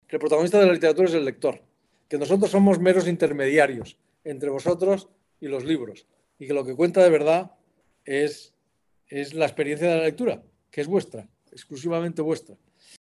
El pasado 19 de abril, los célebres autores Javier Cercas y Lorenzo Silva compartieron con los lectores y lectoras ciegos de los más de 30 Clubes de Lectura (braille y sonoro) que la ONCE tiene en el país una tarde en la que, ambos escritores conversaron y compartieron sus experiencias literarias en el ya tradicional encuentro literario online con motivo del Día del Libro.